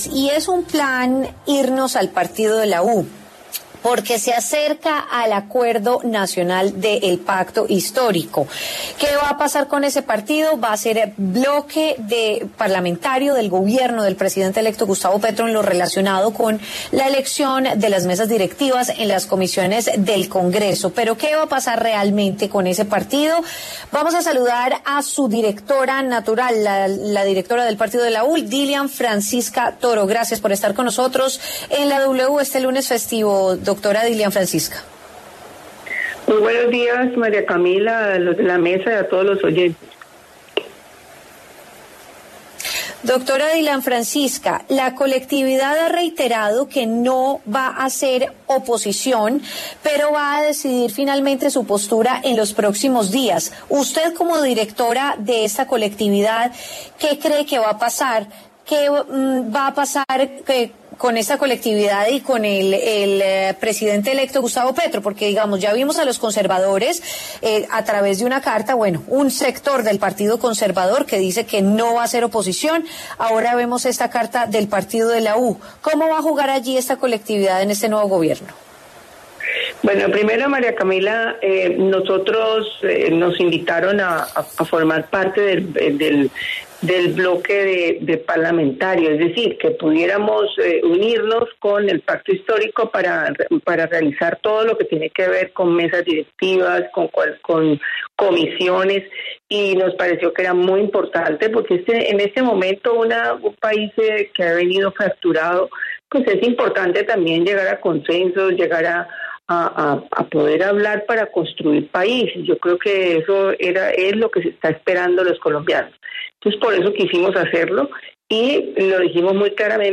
Dilian Francisca Toro habló en La W sobre el futuro del Partido de la U tras anunciar que la bancada electa de congresistas será parte de la coalición parlamentaria del gobierno del presidente electo, Gustavo Petro.